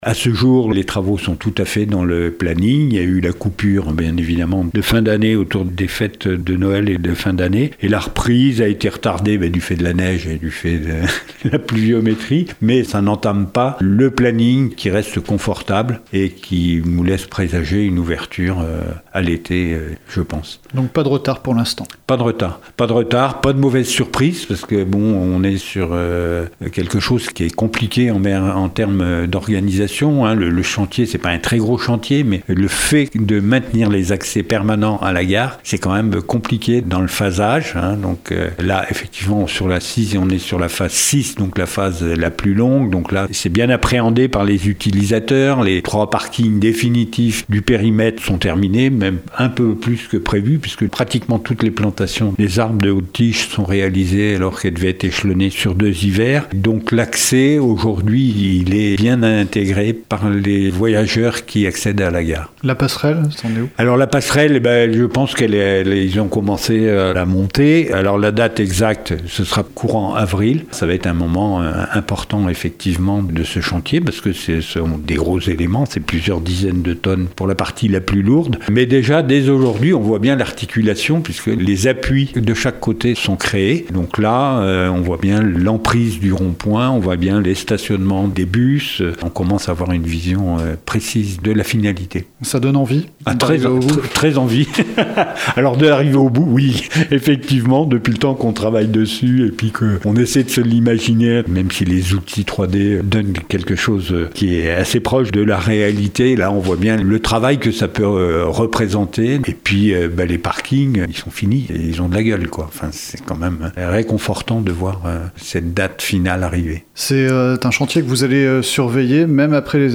Aucun retard dans les délais de livraison, toujours prévus pour cet été, affirme Jean Gorioux, président de la Communauté de communes Aunis Sud, la collectivité qui porte ce projet structurant pour le territoire. Il a répondu à nos questions.